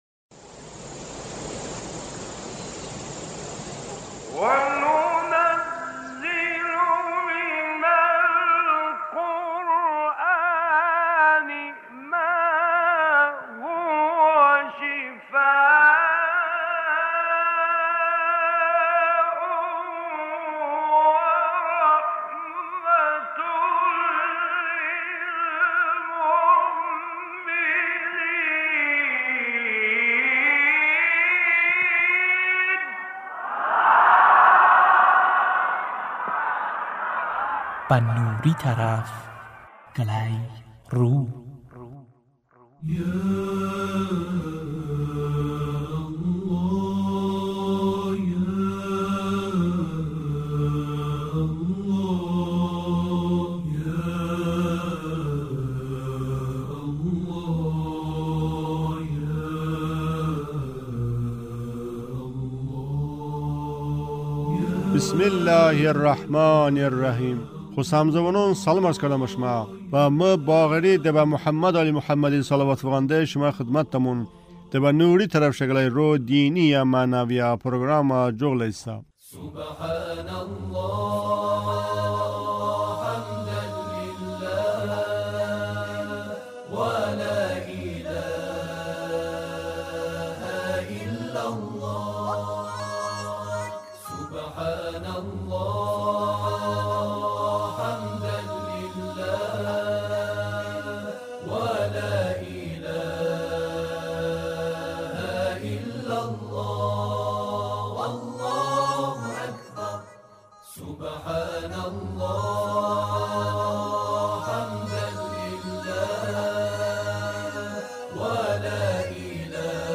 Rəvoyətonədə Yasini mıborəkə surə de “Ğıroni ğəlb”-i nomi zikr bıə. sıftədə bə çın surə 1-ayəku de tobə 4-ə ayəon tilovəti quş doydəmon: بسم الله الرحمن الرحيم يس (1) Yasin.